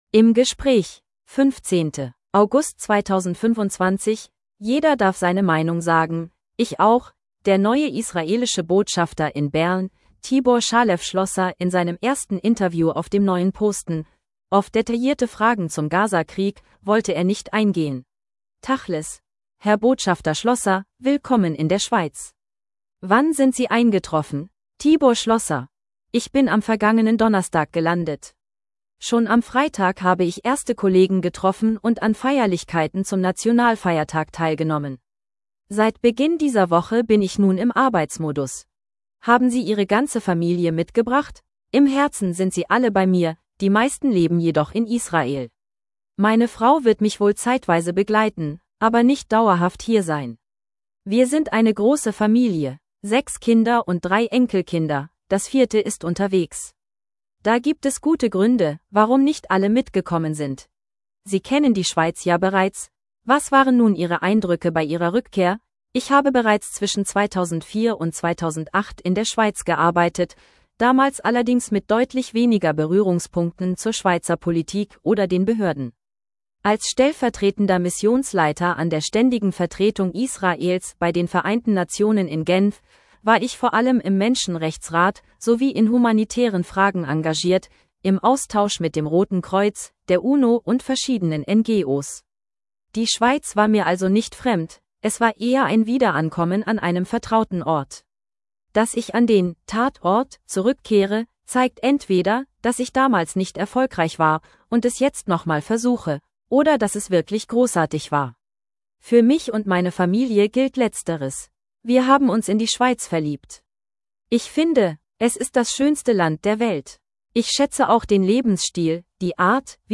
Der neue israelische Botschafter in Bern Tibor Shalev Schlosser in seinem ersten Interview auf dem neuen Posten – auf detaillierte Fragen zum Gaza-Krieg wollte er nicht eingehen.